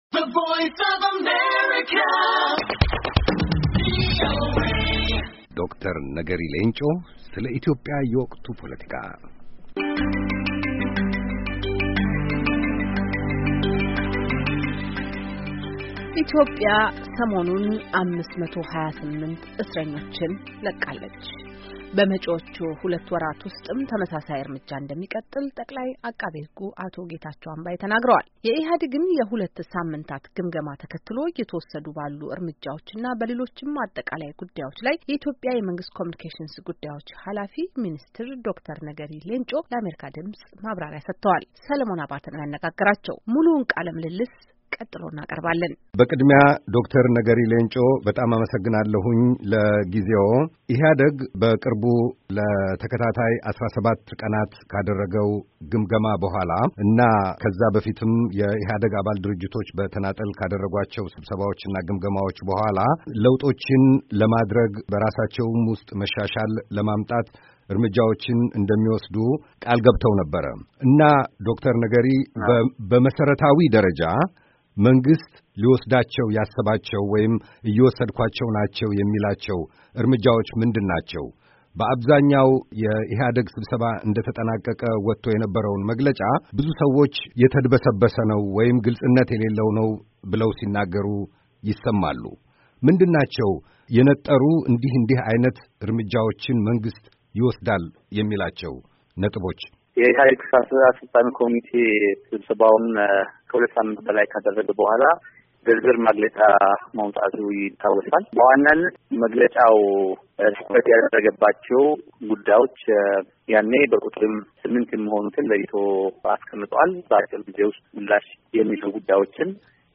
Dr. Negeri Lencho, Minister of the Office of Government Communications Affairs of Ethiopia speaking to VOA on current political situation of the country.